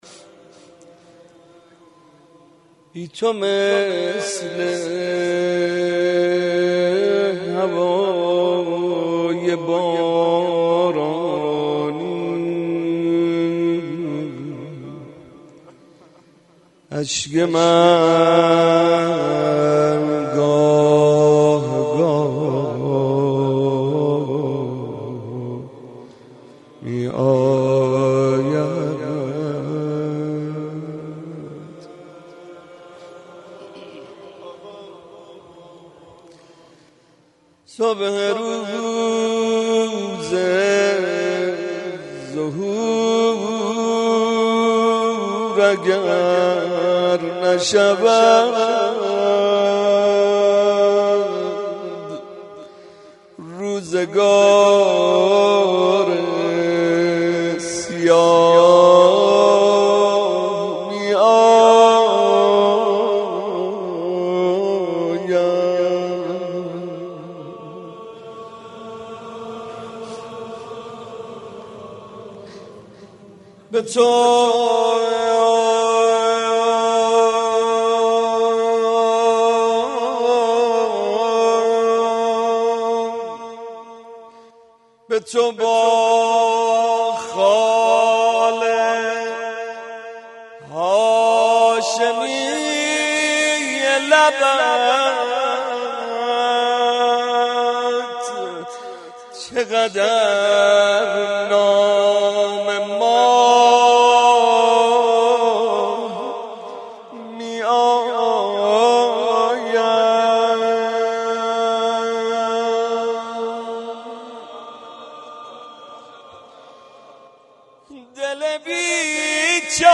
01.monajat.mp3